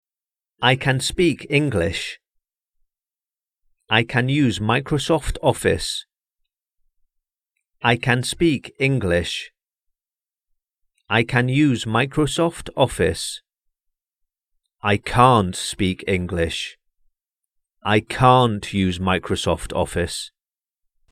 Pronunciation
Remember that the pronunciation changes if it is stressed or unstressed as well as if it is used in the negative.